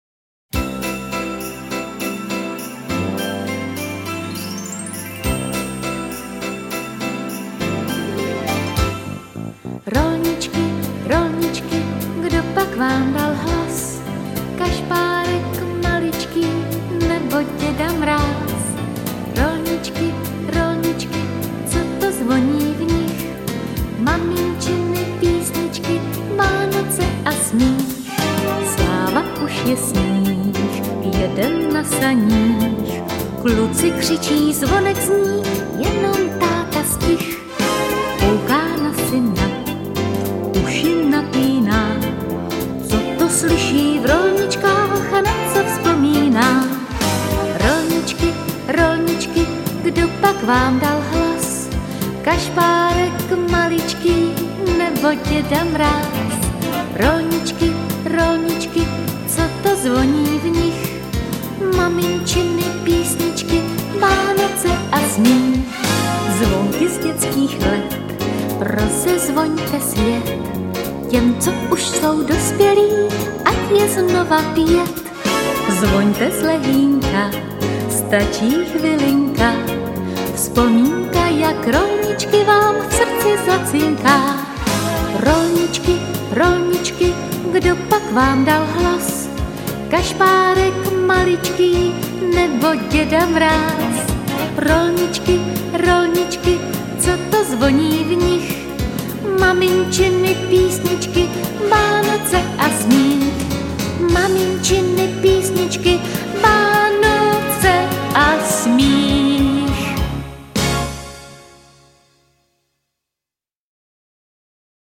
Koledy a písně k nejkrásnějšímu svátku roku!